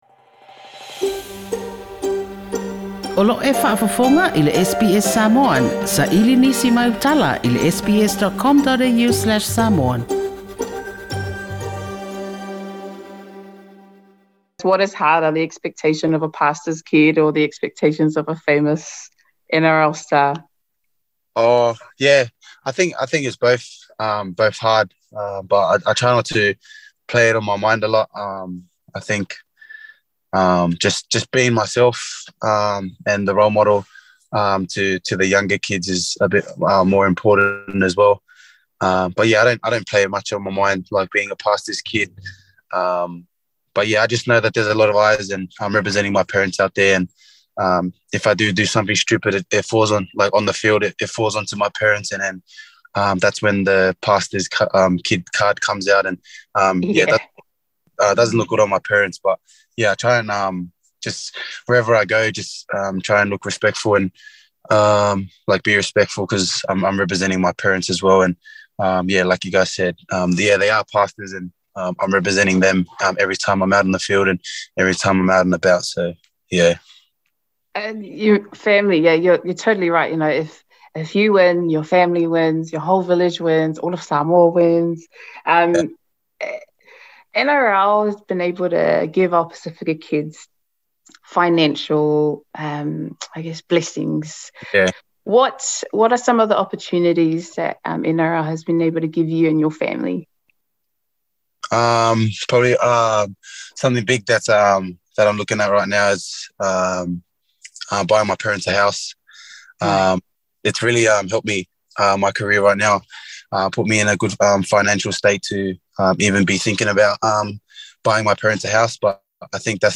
TALANOA with Panthers and NSW Blues star Stephen Crichton.